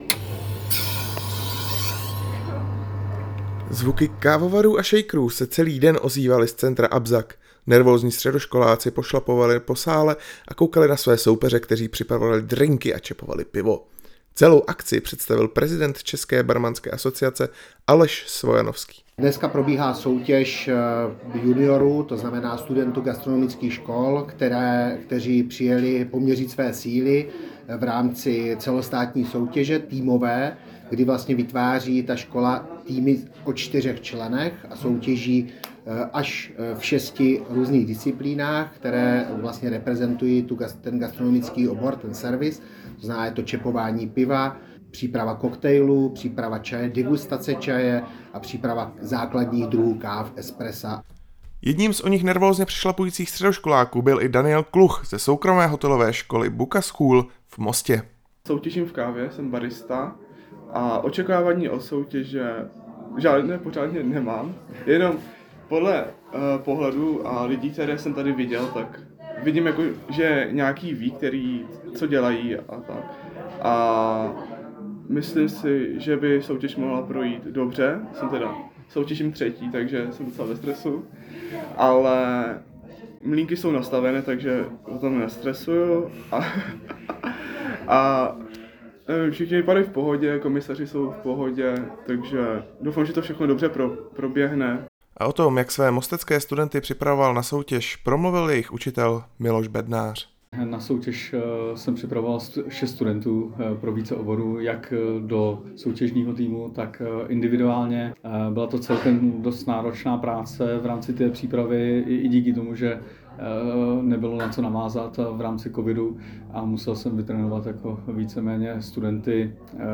Studenti gastronomických škol se včera ve vzdělávacím centru Abzac utkali o první trofej Czech Bartenders Association Team Challenge. Vítězem soutěže se stal tým SOU a SOŠ Polička.
Reportáž: Nejlepší středoškolské barmany a baristy mají v Poličce